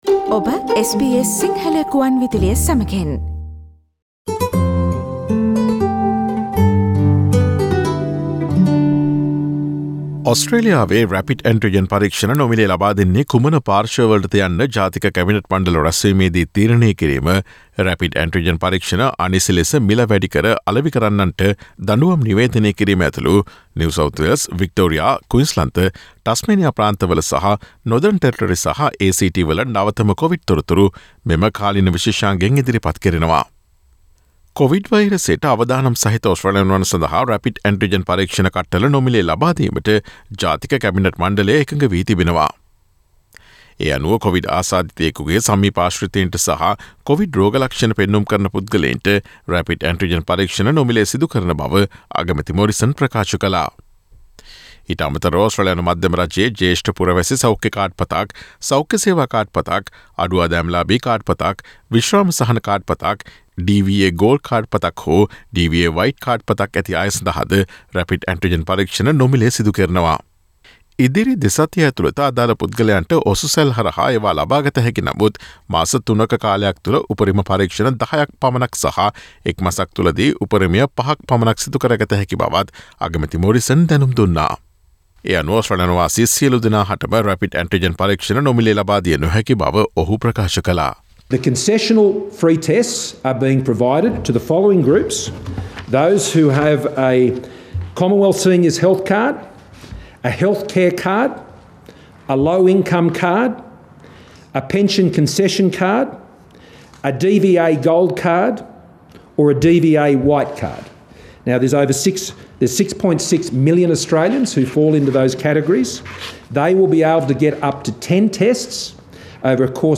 ඔස්ට්‍රේලියාව තුළ Rapid antigen පරීක්ෂණ භාවිතය සඳහා මධ්‍යම රජයේ නවතම තීරණ ඇතුළුව ඔස්ට්‍රේලියාවේ නවතම කොවිඩ් තතු විත්ති රැගත් ජනවාරි 06 වන දා බ්‍රහස්පතින්දා ප්‍රචාරය වූ SBS සිංහල සේවයේ කාලීන තොරතුරු විශේෂාංගයට සවන්දෙන්න.